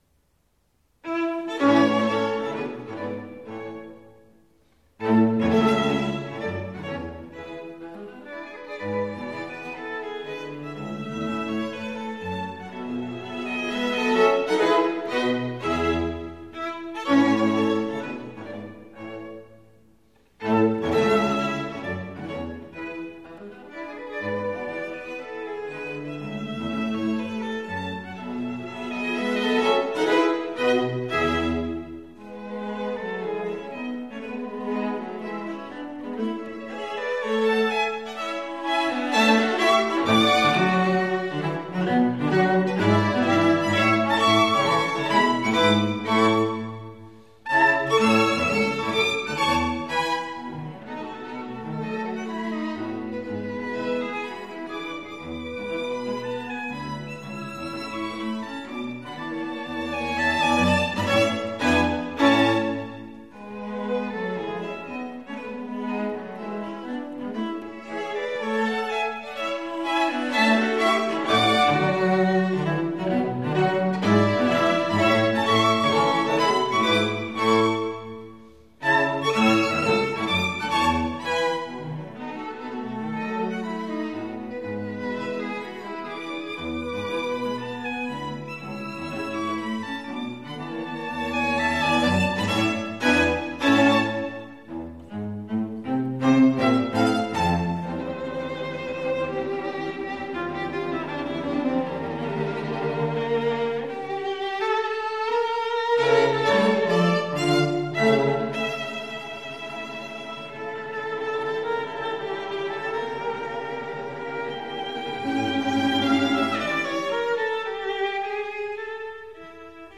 第四乐章 粗声粗气的进行曲使音乐气氛完全与前不同，似乎是从天堂回到 了世俗
乐曲的第二部分，音乐彻底改变性格而成了宣叙调，节 奏也是完全的自由，第一小提琴奏出了即兴式的旋律。